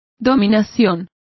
Complete with pronunciation of the translation of mastery.